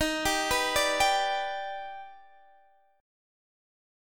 D#+M7 Chord